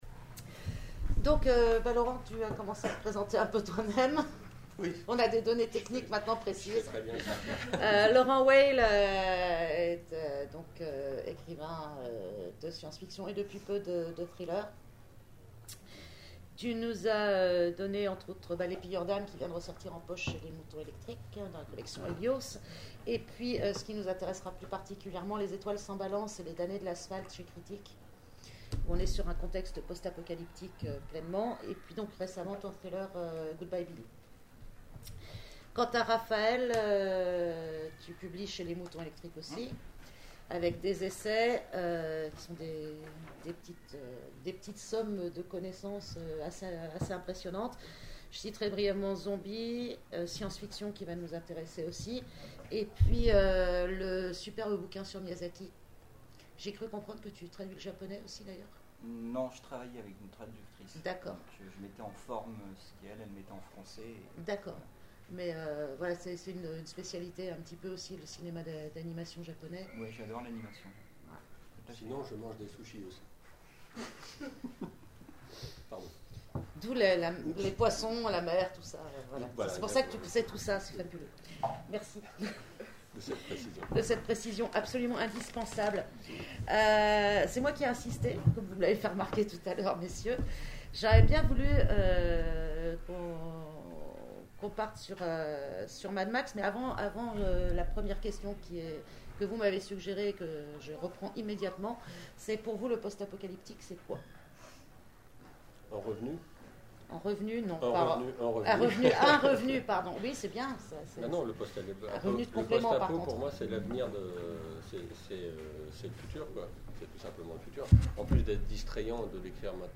Les intergalactiques 2014 : conférence L’écologie du monde post-apocalyptique au cinéma